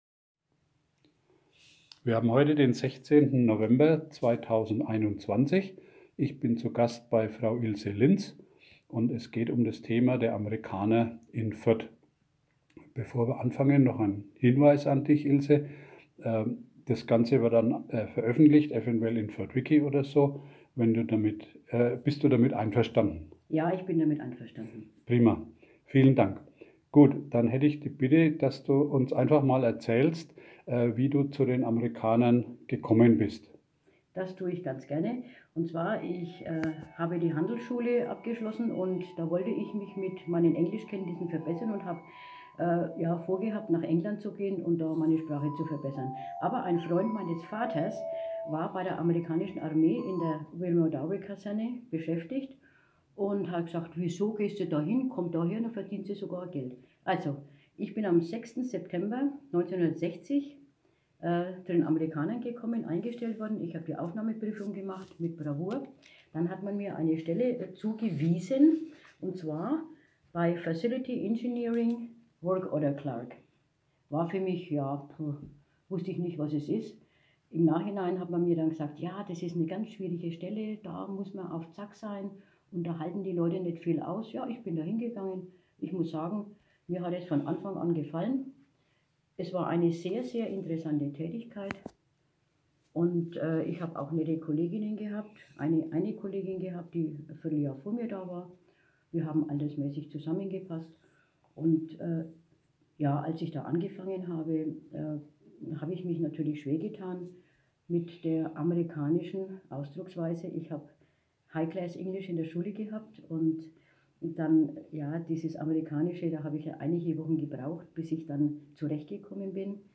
Zeitzeugenberichte